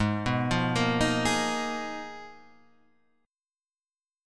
Abm6add9 Chord
Listen to Abm6add9 strummed